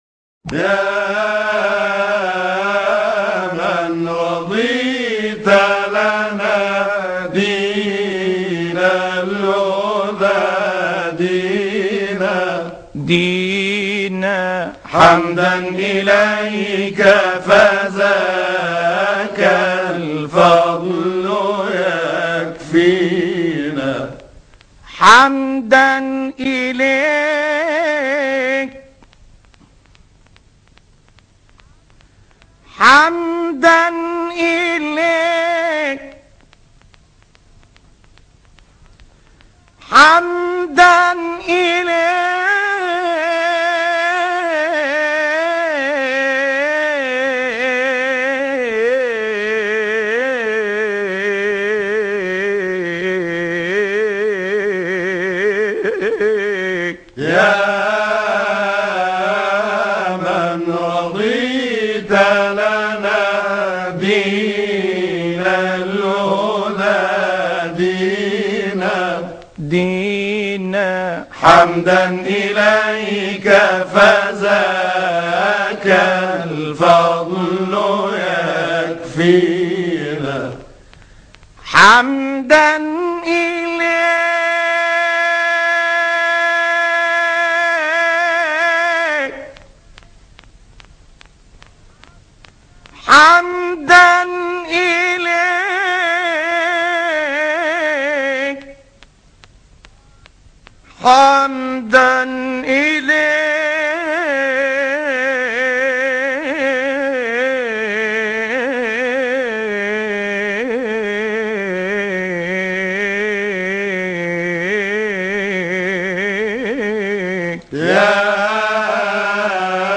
الشيخ نصر الدين طوبار (1920 - 16 نوفمبر 1986) قارئ قرآن ومنشد ديني مصري، من مواليد المنزلة بمحافظة الدقهلية.
ابتهالات الشيخ نصر الدين طوبار | يا من رضيت لنا ÇÓÊãÇÚ